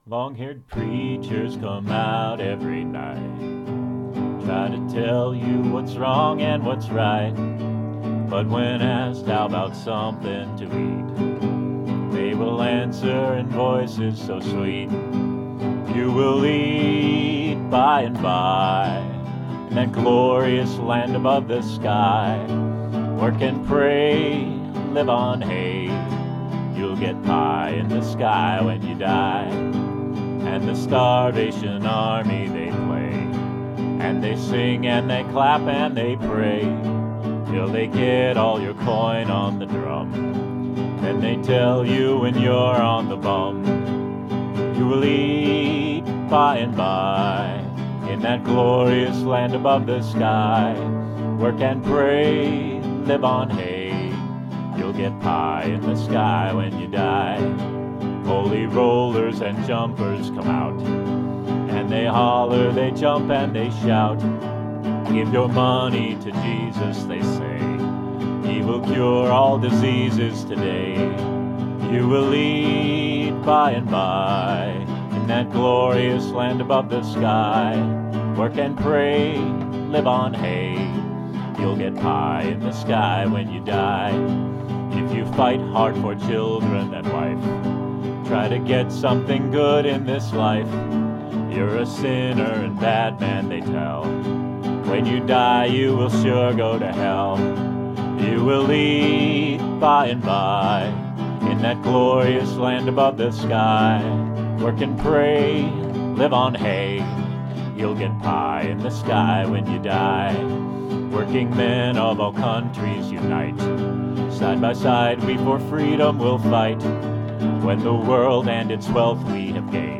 Solidarity at Recall Scott Walker Demonstration in WI.